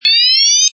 Descarga de Sonidos mp3 Gratis: alarma 8.